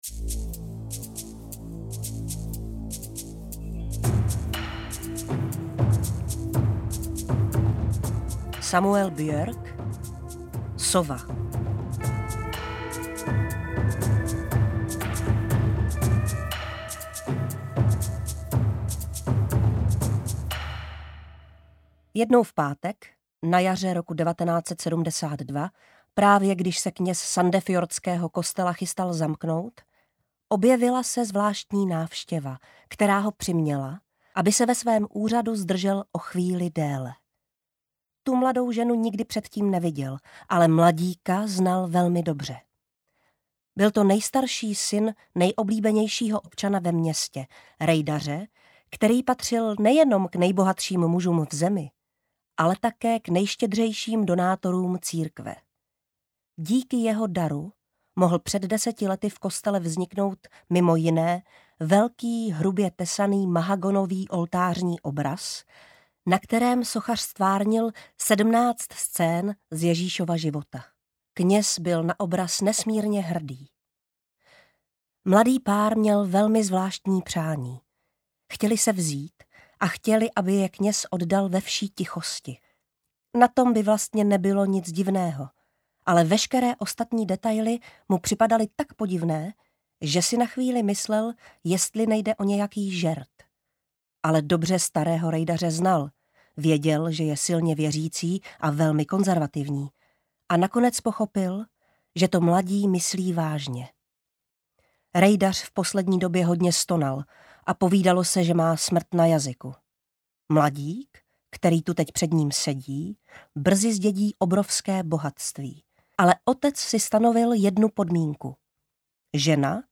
Interpret:  Petra Špalková
Interpretka je výborná a poslech i po této stránce dokonalý!
Poslouchala jsem jako audioknihu.
:-) Velmi dobře interpretováno.